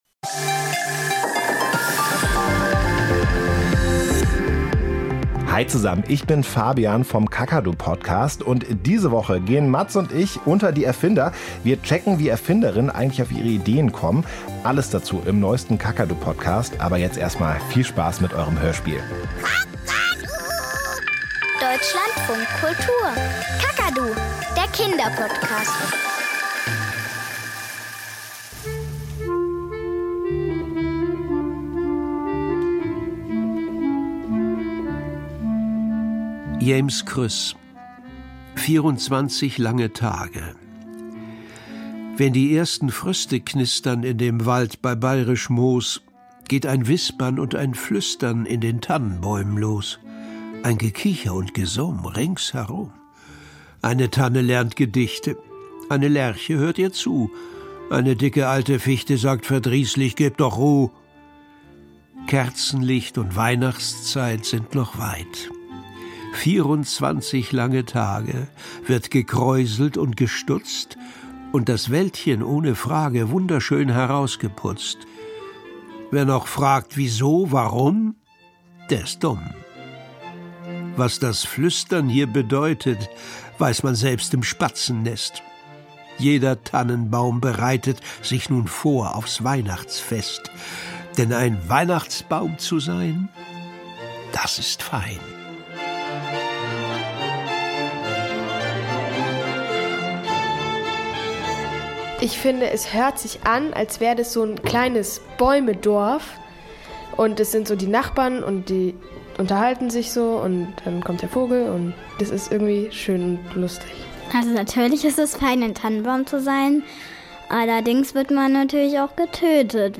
Kinderhörspiel
Kinder haben sich mit alten und neuen Weihnachtsgedichten beschäftigt. Vergnügt, besinnlich, überraschend und festlich, einfach weihnachtlich.